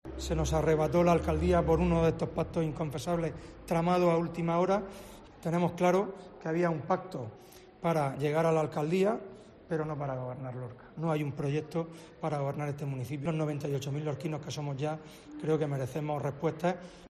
Fulgencio Gil, portavoz PP